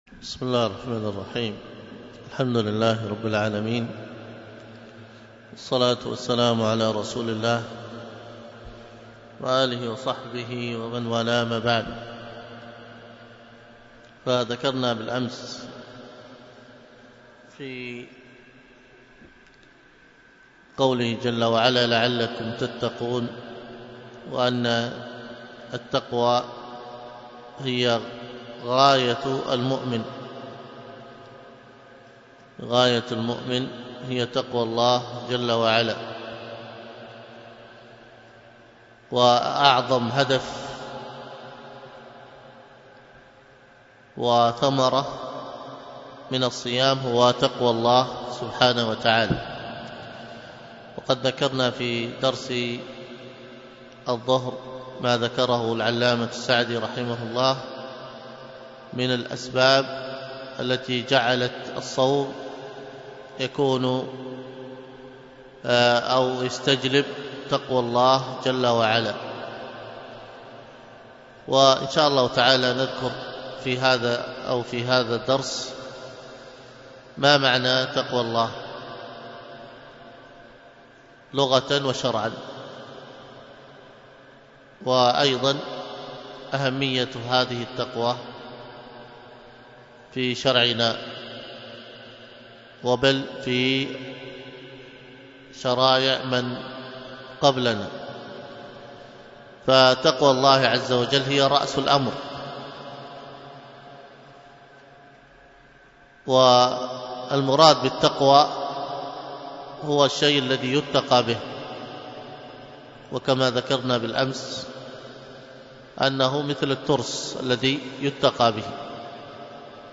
الخطبة بعنوان أهمية الدعاء أول النهار، وكانت بمسجد التقوى بدار الحديث بالشحر 1 رجب 1440هـ